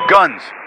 Radio-pilotLaunchGuns1.ogg